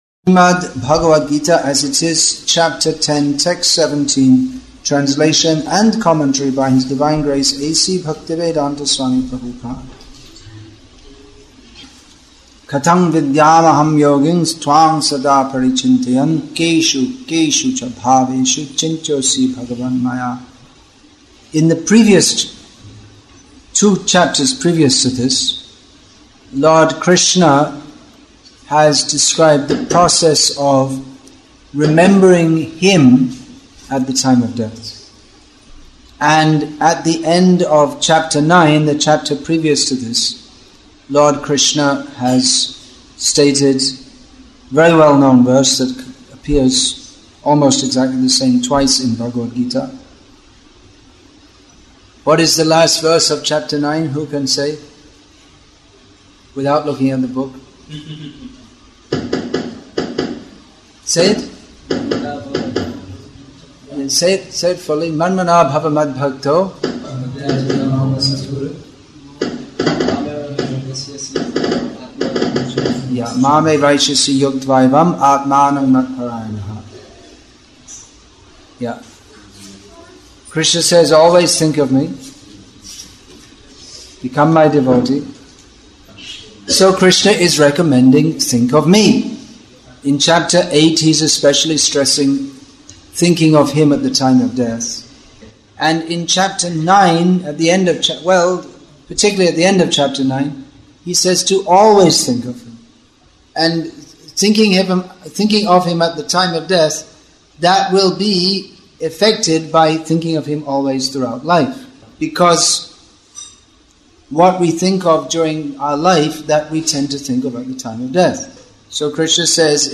English with தமிழ் (Tamil) Translation; Vellore, Tamil Nadu , India